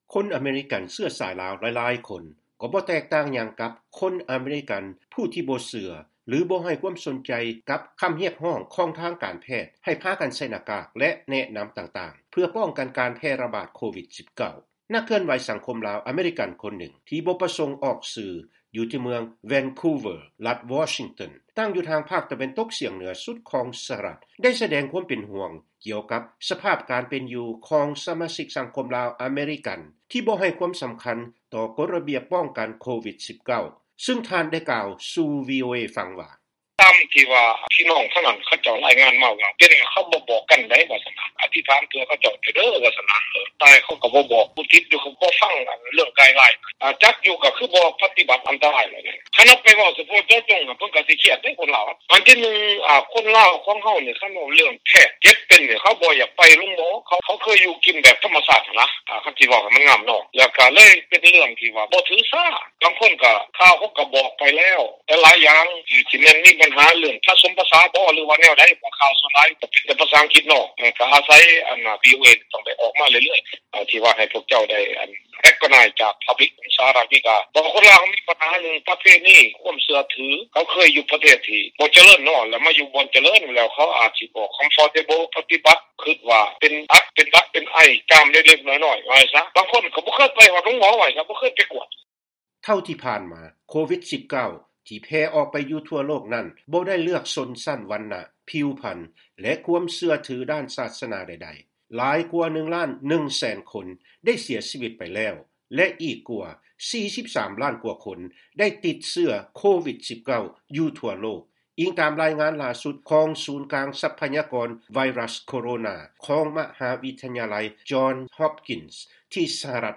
ເຊີນຟັງຄຳໃຫ້ການຈາກ ນັກເຄື່ອນໄຫວສັງຄົມລາວອາເມຣິກັນ